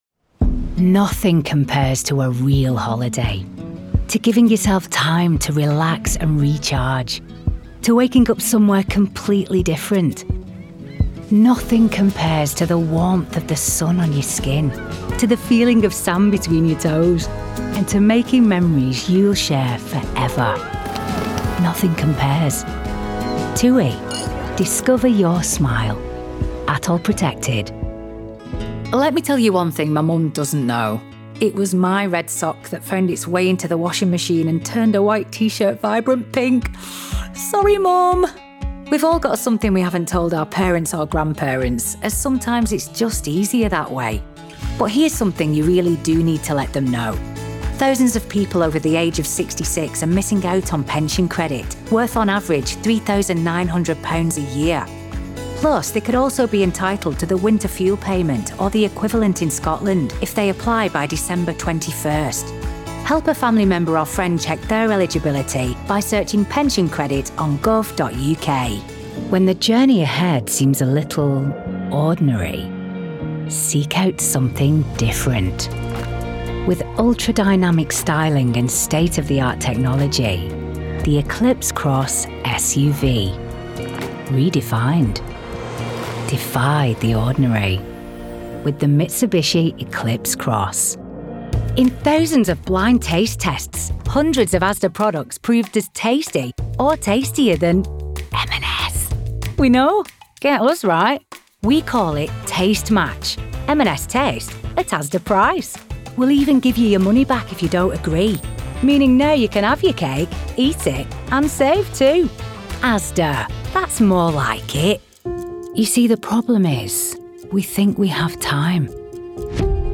Engels (Brits)
Natuurlijk, Opvallend, Veelzijdig, Vriendelijk, Warm
Commercieel
A naturally northern voice that CONNECTS with warmth, depth and authenticity. Dial-down to soft, neutral northern or dial-up to broad Lancashire/Manchester.
Your 'go-to' northern voice!